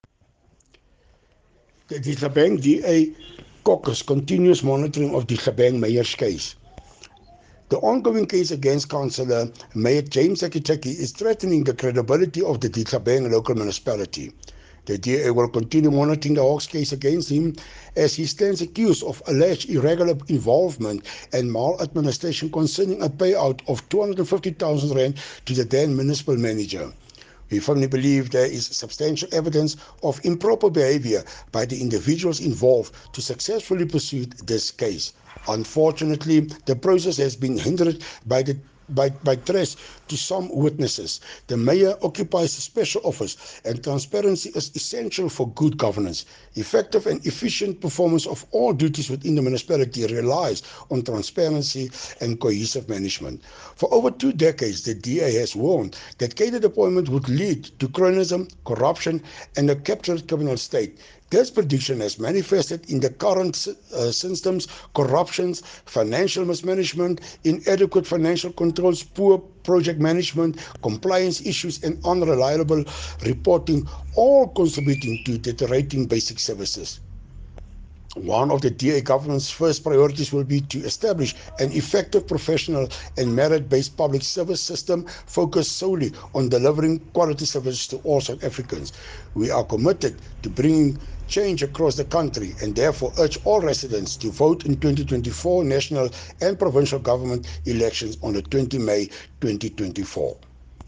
Afrikaans soundbites by Cllr Hilton Maasdorp and